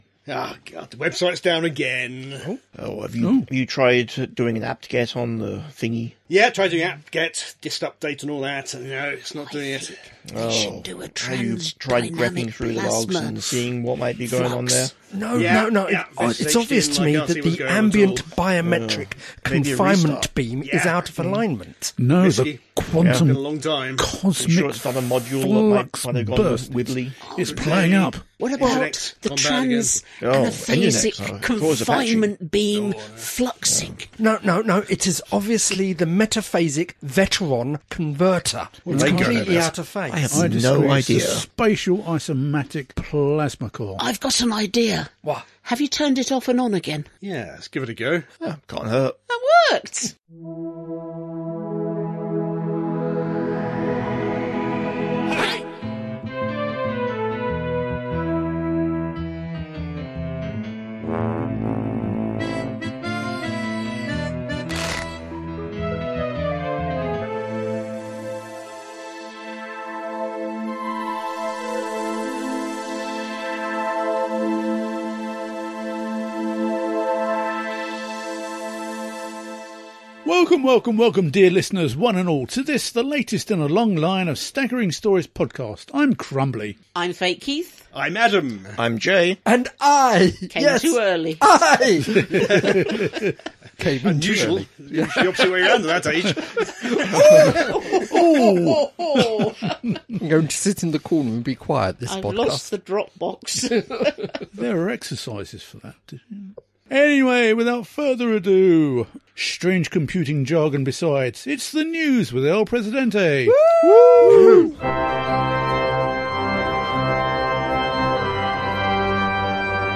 00:00 – Intro and theme tune.
53:49 — End theme, disclaimer, copyright, etc.